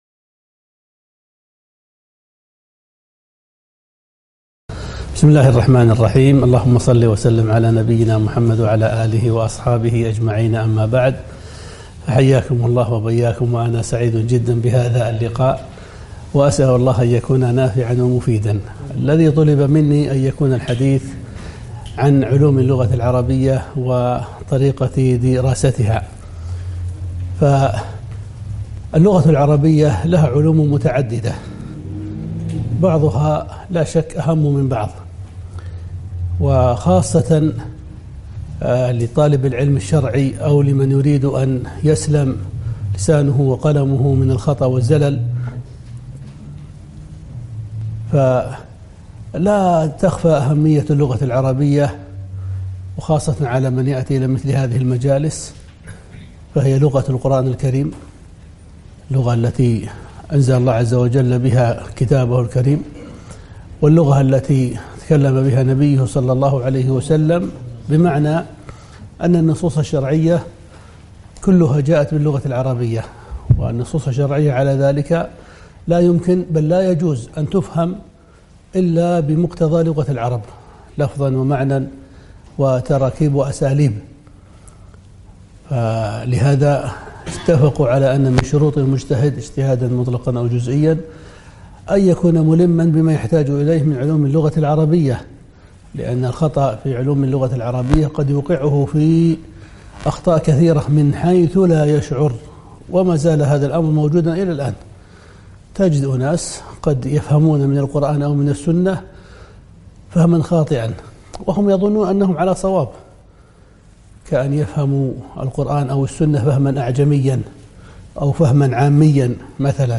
محاضرة - حاجة طالب العلم لعلوم العربية وكيفية تدرجها فيها